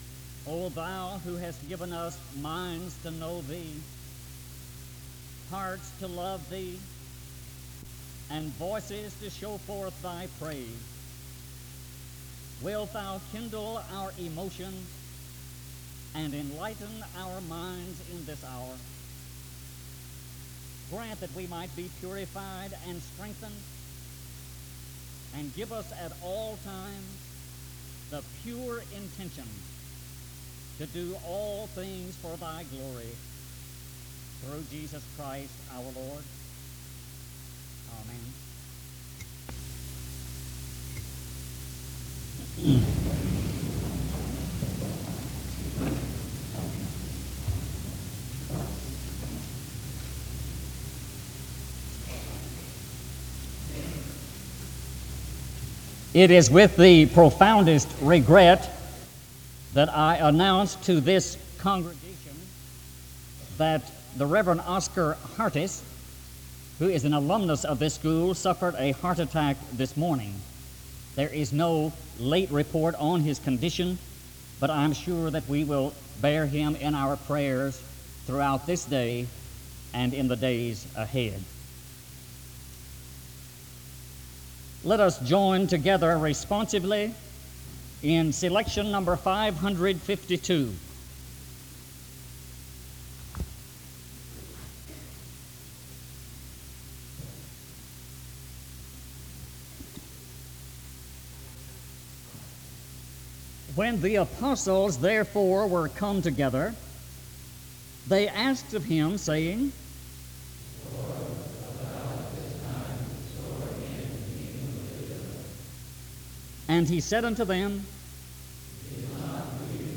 File Set | SEBTS_Adams_Lecture_Gardner_C_Taylor_1977-02-25.wav | ID: 331686e4-2be0-4e31-ad48-db335634e5d7 | Hyrax